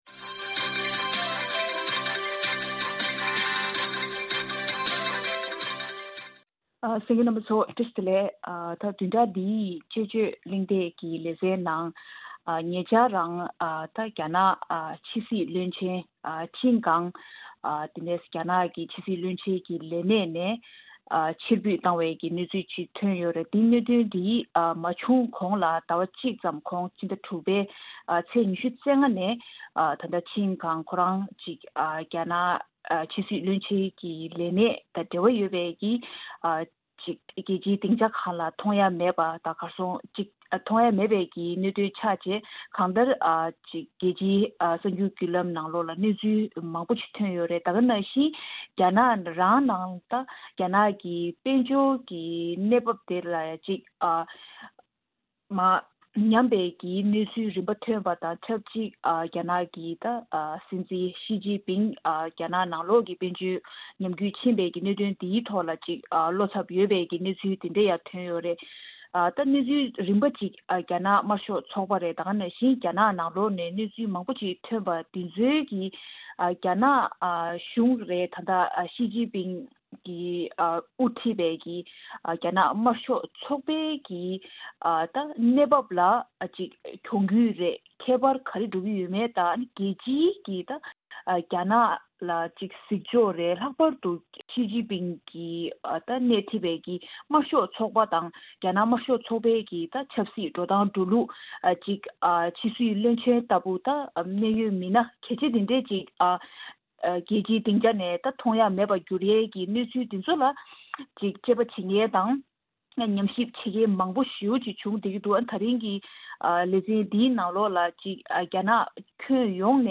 གླེང་མོལས་ཞུས་པར་གསན་རོགས་གནང་།